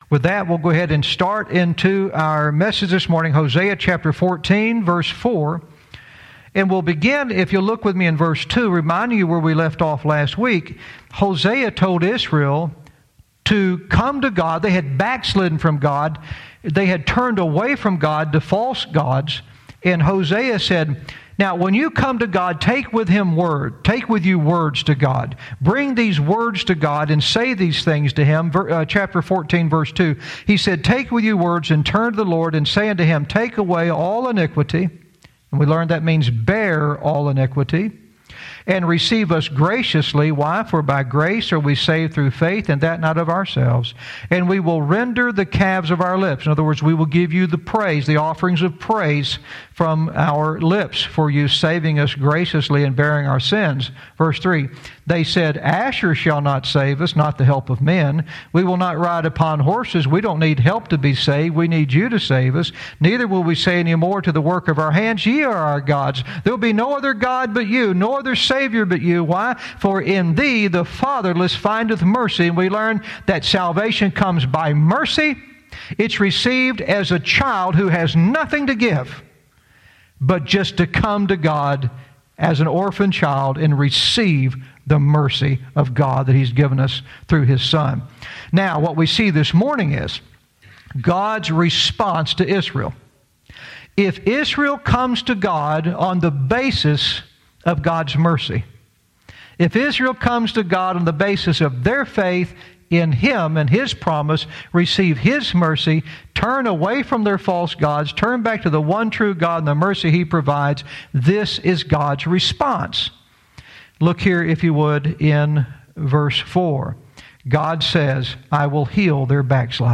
Verse by verse teaching - Hosea 14:4 "Everything in Its Place"